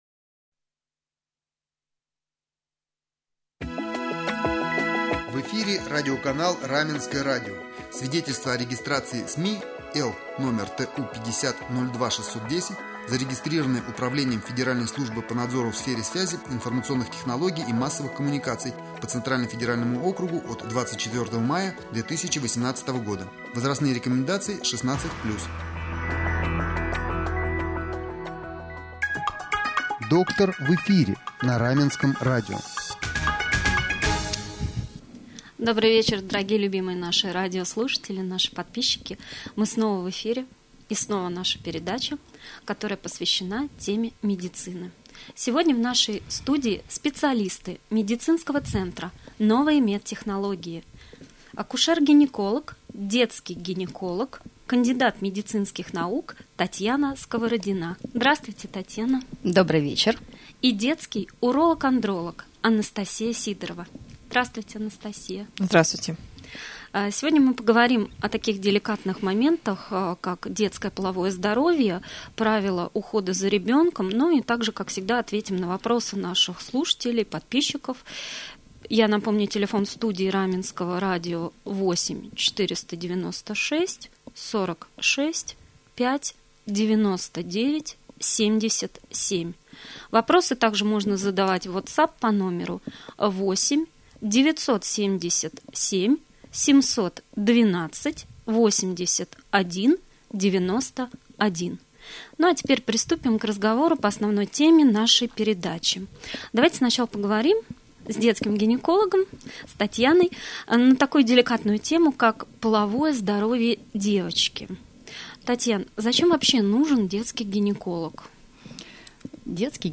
В прямом эфире
doktor-v-jefire-9-ijunja-detskij-ginekolog-detskij-androlog.mp3